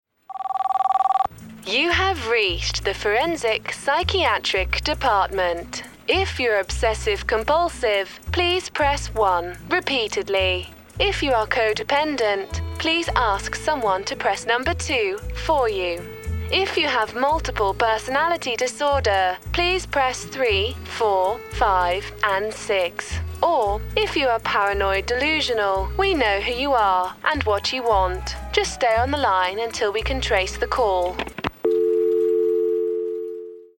I have a bright, friendly and engaging vocal quality which has a natural warmth and energy to it. Native English RP
britisch
Sprechprobe: eLearning (Muttersprache):